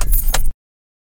Cars / key2.ogg
key2.ogg